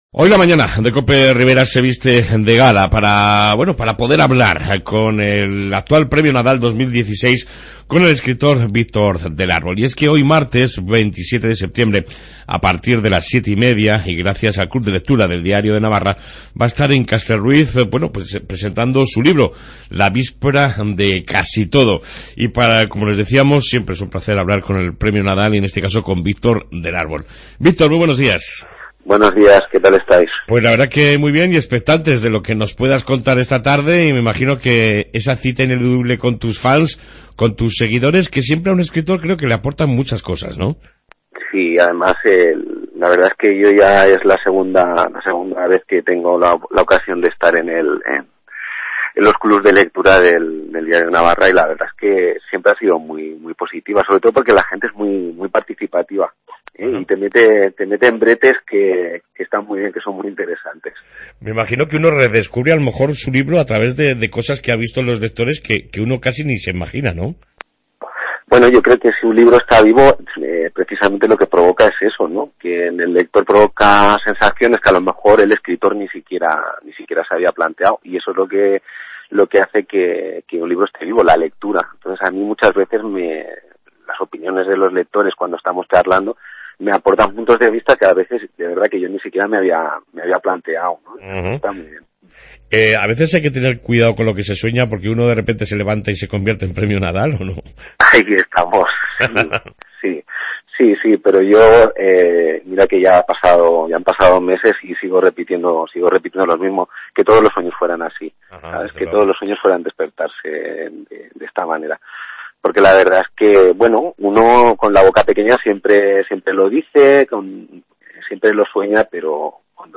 AUDIO: Entrevista a Victor del Árbol, flamante premio Nadal 2016, que visita hoy nuestra ciudad gracias al Club de Lectura de Diario de Navarra.